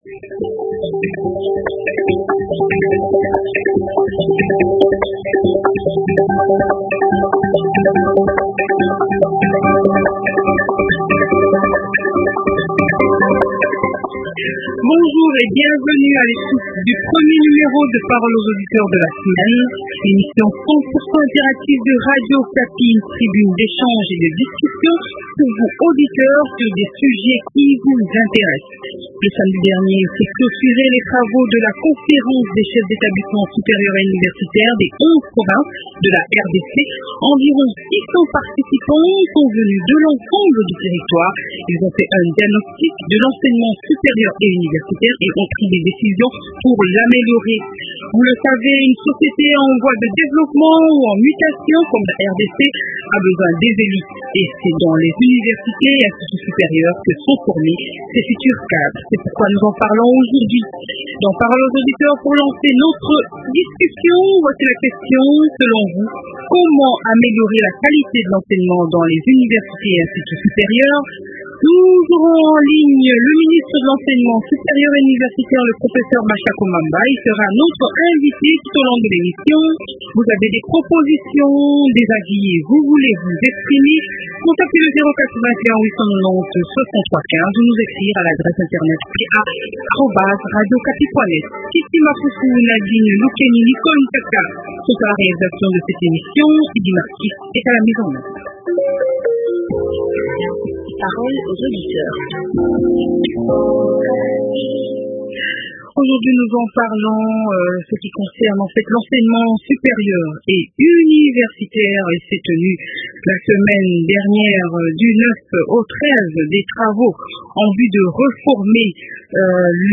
Invité: Professeur Mashako Mamba, ministre de l’Enseignement supérieur et universitaire.